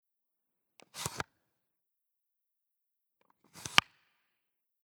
Mobile phone Nokia 8110
Open and close
1316_Auf-_und_zuschieben.mp3